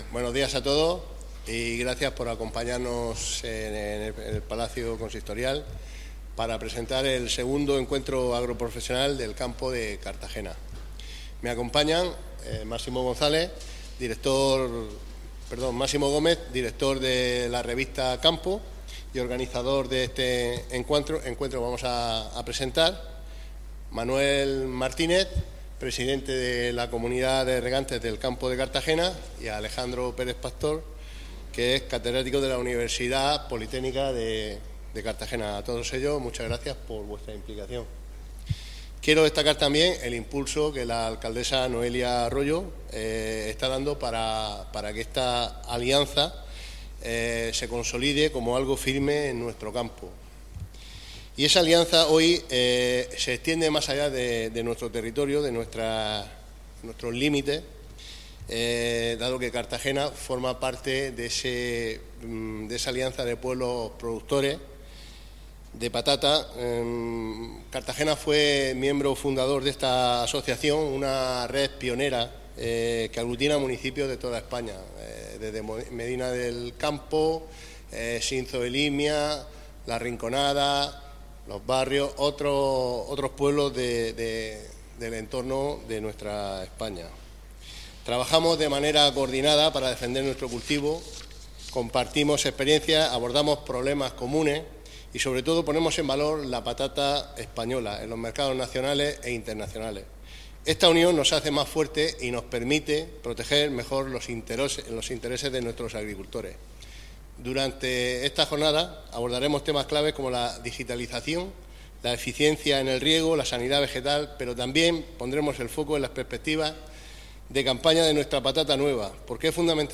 Audio: Presentaci�n de la II Encuentro Agroprofesional de la Patata del Campo de Cartagena (MP3 - 18,53 MB)